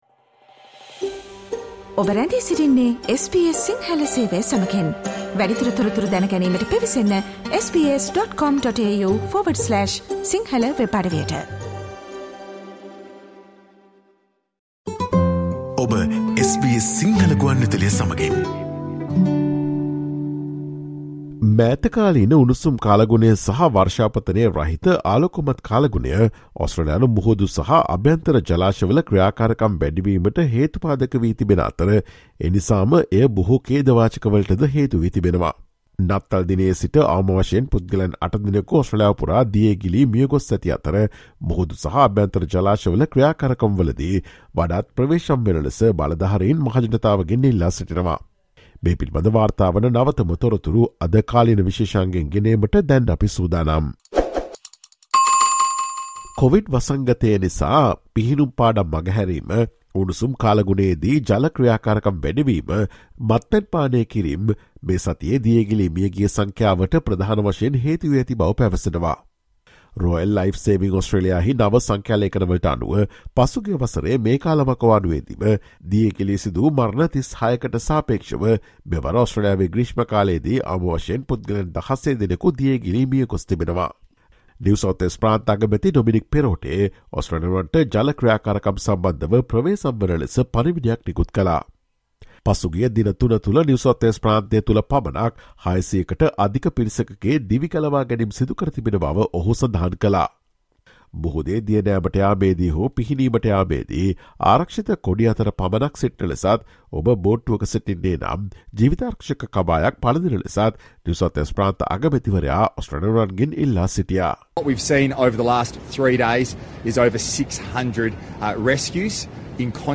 At least eight people have drowned across the country since Christmas Day, leaving authorities pleading with the public to be more cautious. Listen to the SBS Sinhala Radio's current affairs feature on Thursday, 29 December.